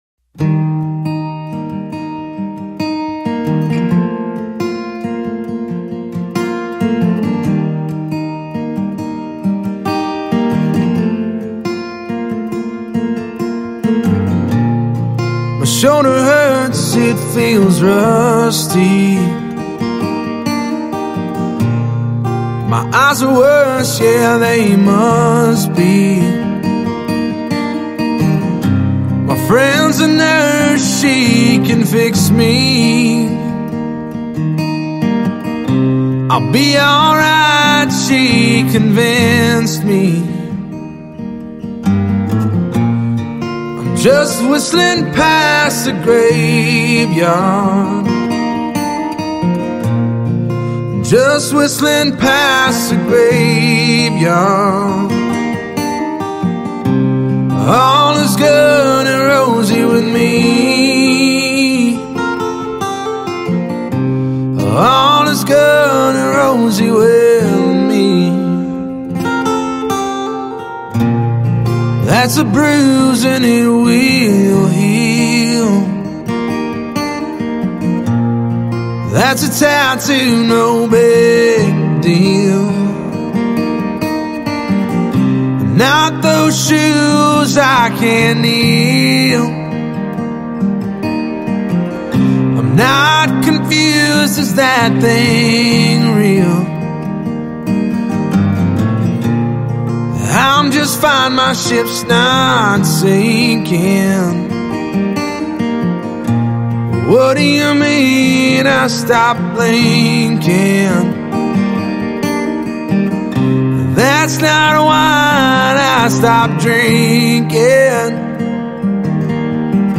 Vocals/Guitar
Real Human Music & Vocals